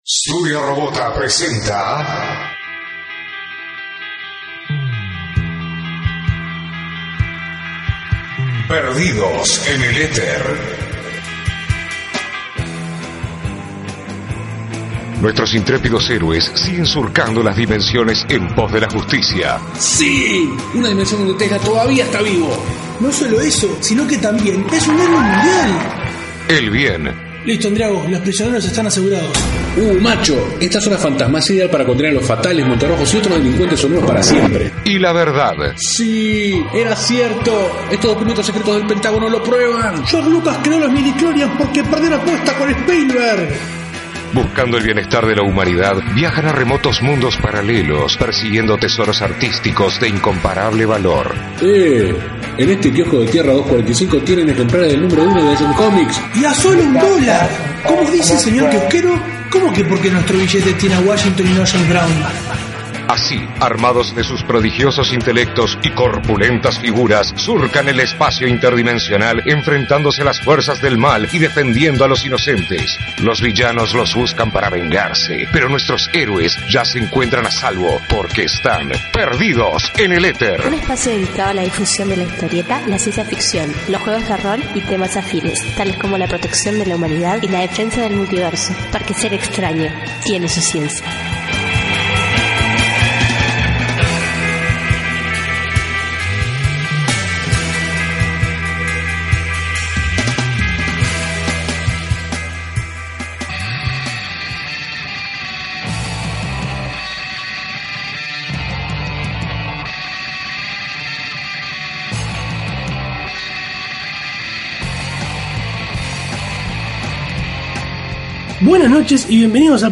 Con temas de discusión traídos por nosotros y otros propuestos por la audiencia, hablamos de Star Trek y choques culturas, juegos de rol, fandom tóxico, Star Wars, alfajores, refuerzos, sanguches, tortas, y más. Disculpen los problemas de audio al final.